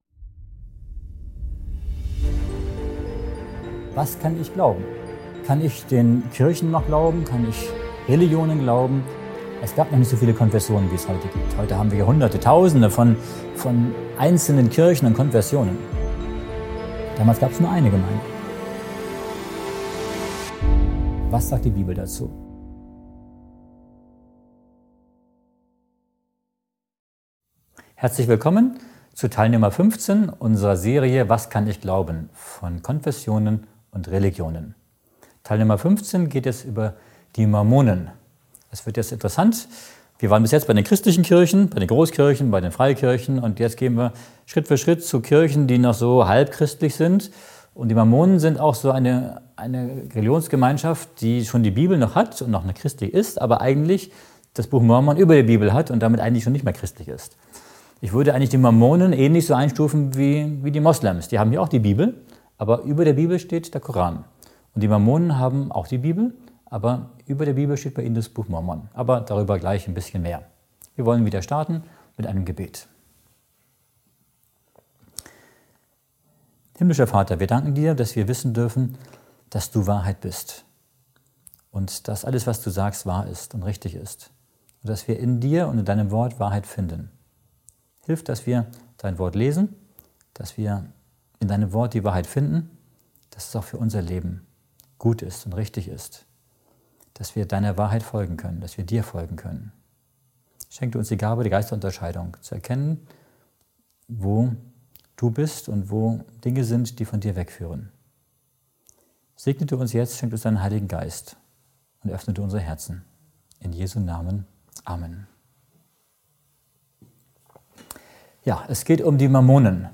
In diesem spannenden Vortrag wird die Welt der Mormonen ergründet. Sie erfahren mehr über die Ursprünge, Lehren und Abweichungen zum biblischen Glauben und die kontroversen Elemente wie das Buch Mormon.